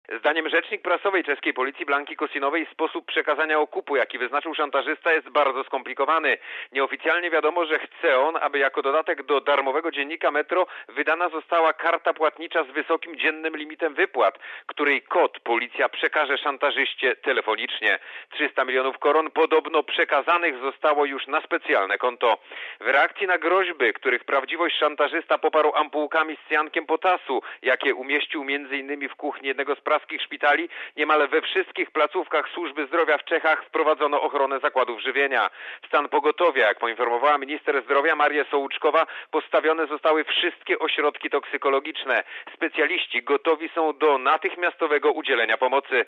(RadioZet) Źródło: (RadioZet) Korespondencja z Pragi (360Kb) Szantażysta domaga się 300 mln koron (42 mln złotych) okupu, grożąc zatruciem cyjankiem potasu jedzenia w szpitalnych stołówkach w Pradze .